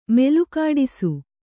♪ melukāḍisu